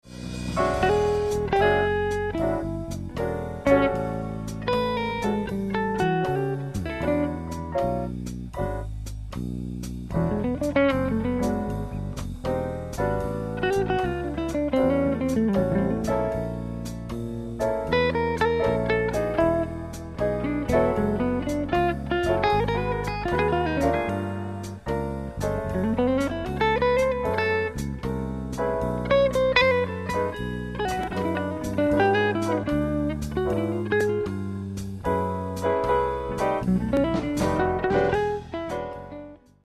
Bref peu importe, je t'ai rapidement fait un truc pensé en arpèges sur un blues majeur en E.
Je n'ai pas utilisé ton playback désolé, il ne swingue pas un cachou.
blues1.mp3